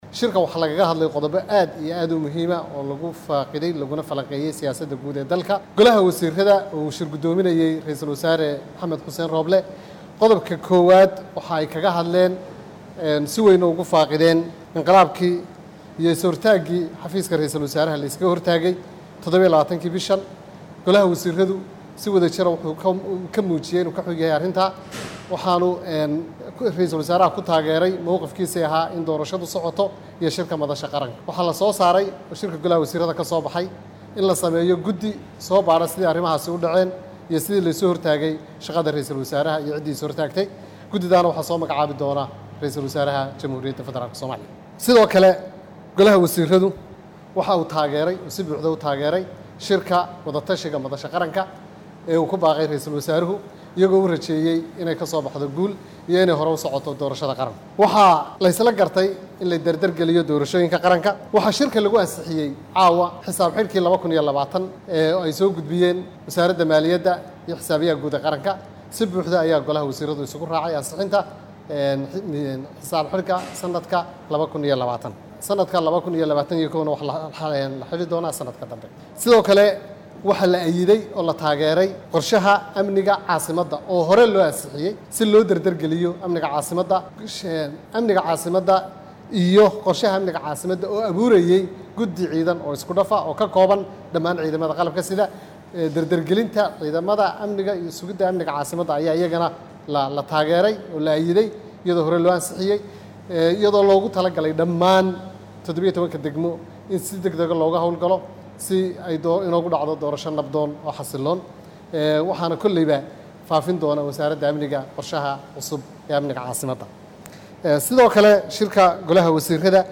Golaha wasiirrada Soomaaliya oo xalay kulan ku yeeshay magaalada Muqdisho ayaa waxay uga hadleen qodobbo dhowr ah oo ku saabsan doorashooyinka, ammaanka, abaaraha, ciidamo la sheegay in ra’iilsul wasaaraha ay is hortaag ku sameeyeen iyo qodobbo kale. Wasiirka wasaaradda warfaafinta xukuumadda Federaalka Soomaaliya Cismaan Abuukar Dubbe ayaa shirka markuu soo dhammaaday faahfaahin ka siiyay warbaahinta qodobbadii looga hadlay.